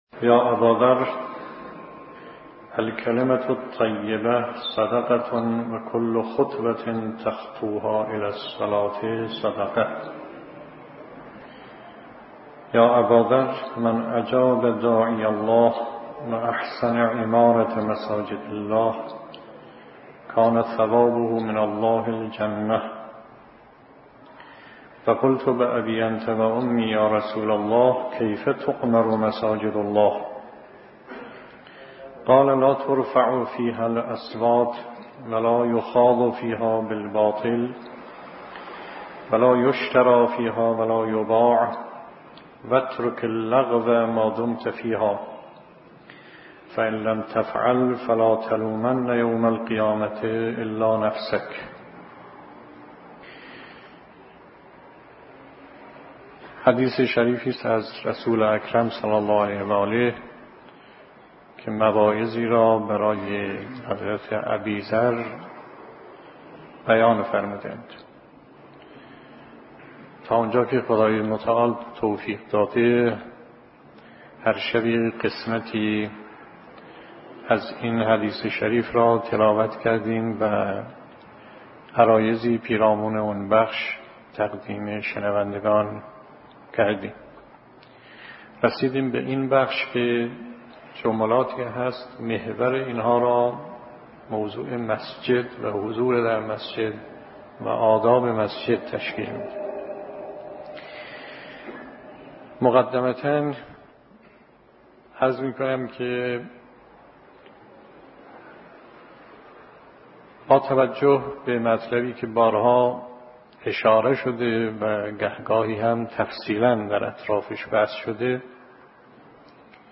فایل سخنرانی از آیت الله مصباح یزدی